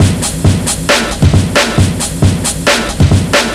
Vinyl Break 135.wav